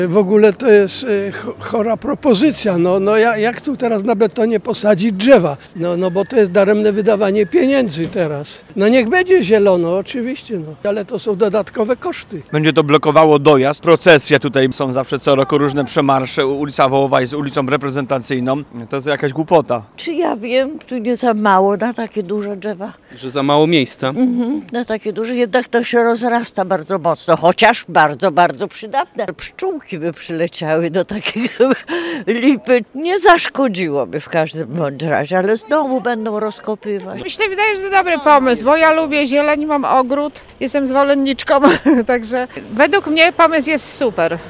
Zdania mieszkańców Tarnowa w temacie sadzenia drzew na ulicy Wałowej są mocno podzielone.
25sonda_news.mp3